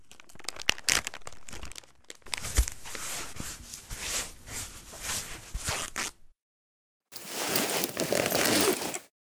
bandage_surginst.ogg